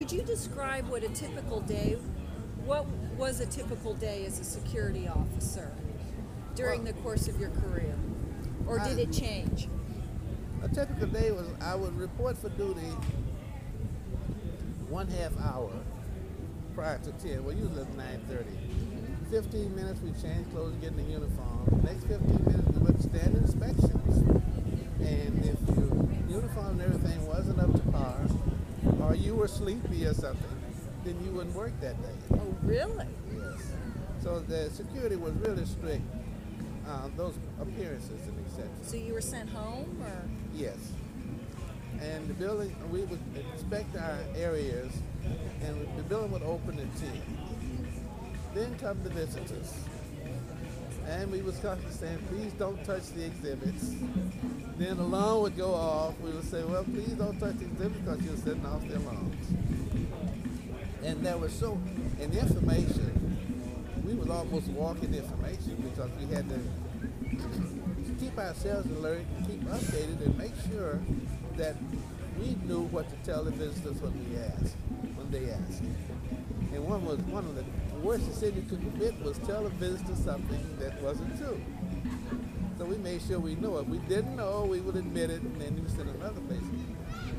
Festival of American Folklife 1996: Working at the Smithsonian
Outside on the National Mall an interviewer sits across a table from two men with two dogs.